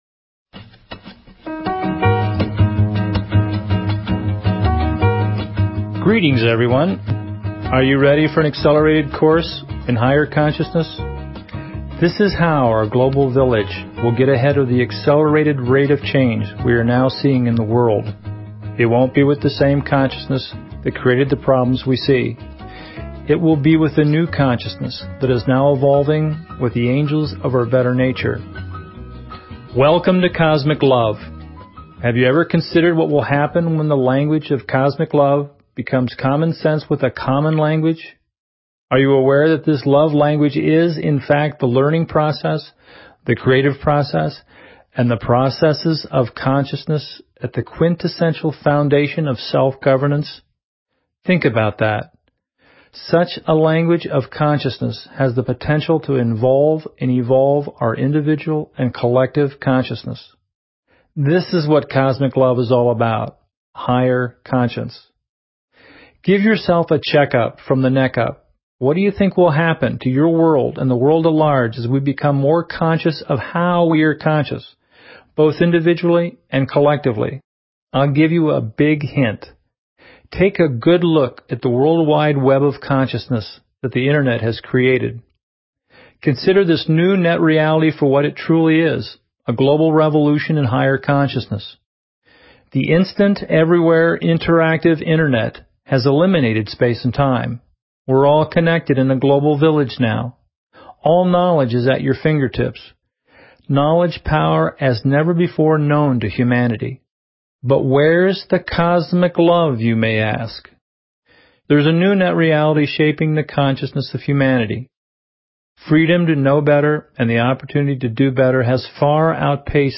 Talk Show Episode, Audio Podcast, Cosmic_LOVE and Courtesy of BBS Radio on , show guests , about , categorized as